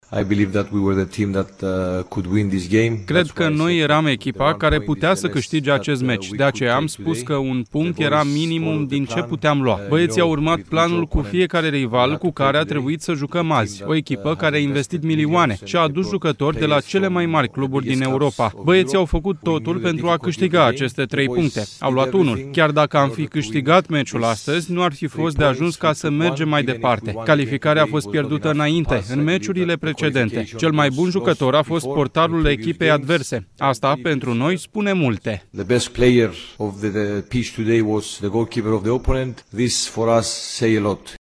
Antrenorul FCSB, Elias Charalambous:  „Calificarea a fost pierdută înainte, în meciurile precedente”